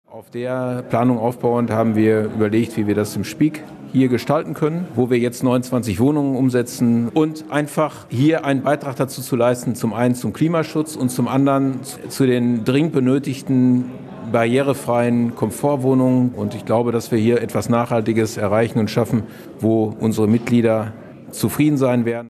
Das hörte man beim Richtfest des Neubaus von 2 Mehrfamilienhäusern in Hohenlimburg. Auf 3000 Quadratmetern entstehen hier 29 neue Wohnungen.